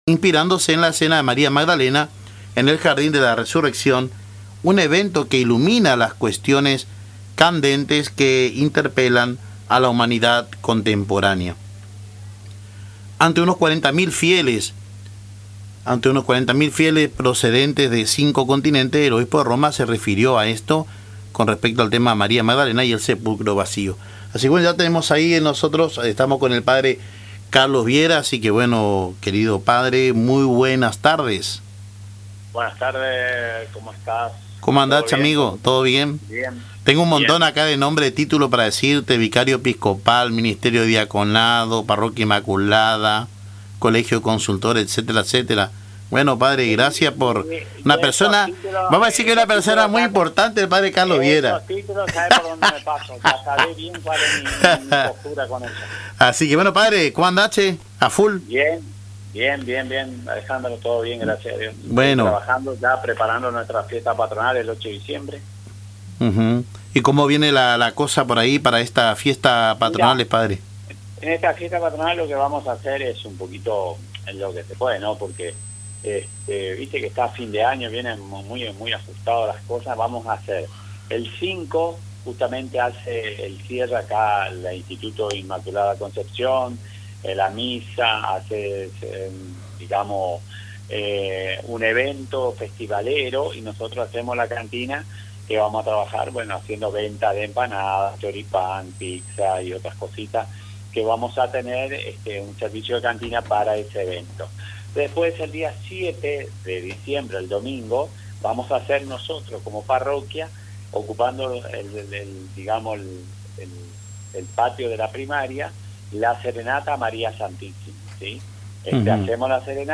En diálogo con Caminando Juntos por Radio Tupambaé